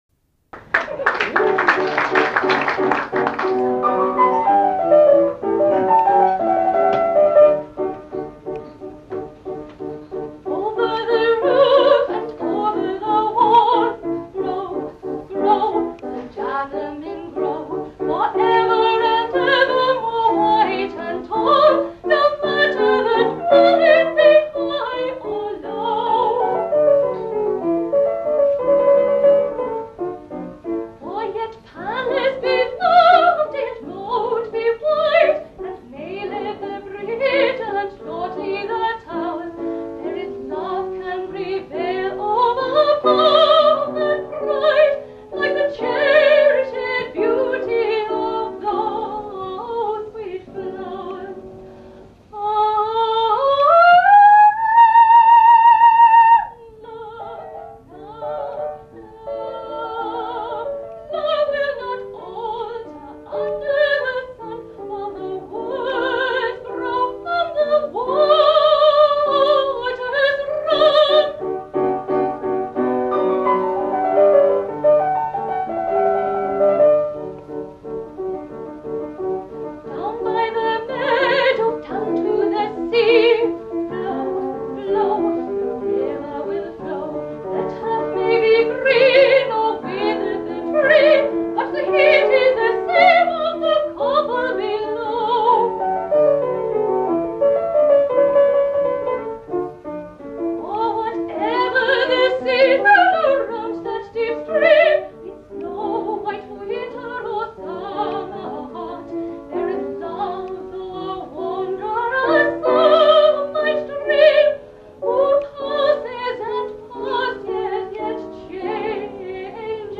the Overture was published in an arrangement for wind band, whilst the vocal score of the soprano aria     ‘Over the roof’ and a madrigal ‘When love and beauty’ were printed by Cramer and Novello respectively.
at the Sullivan Society Festival in 1999